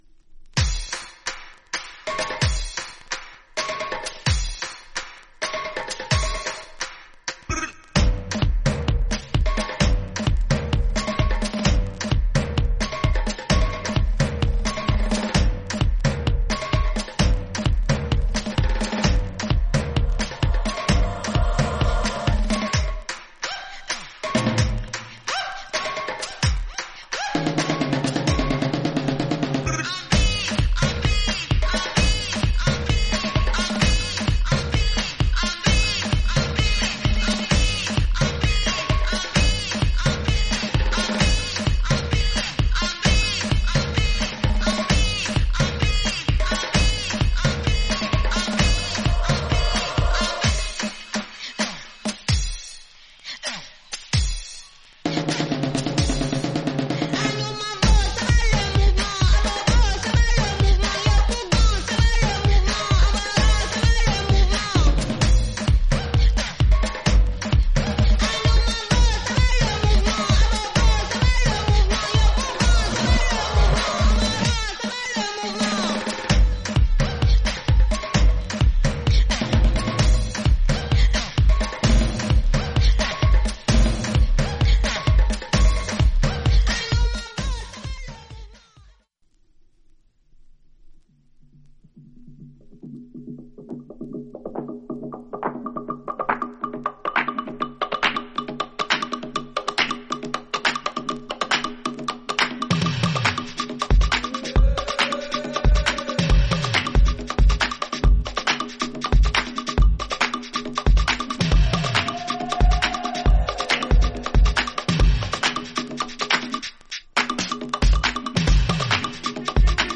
盤面薄いスレが少しありますが音に影響ありません。